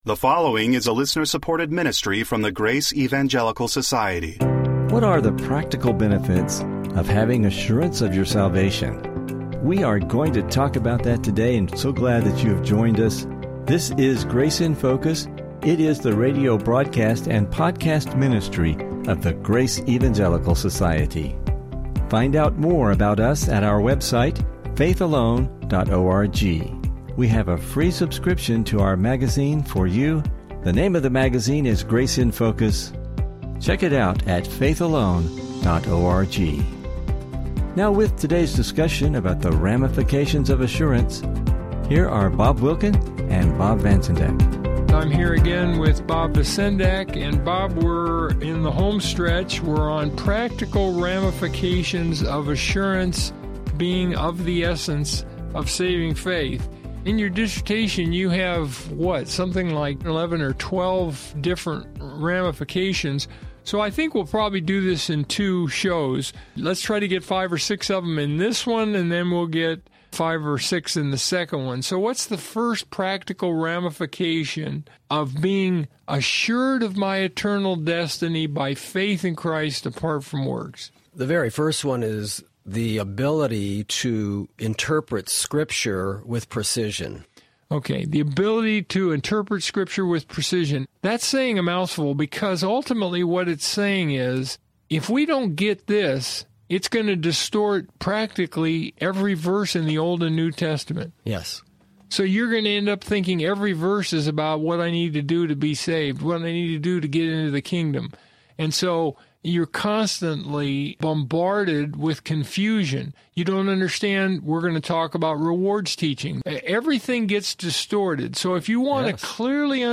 Fear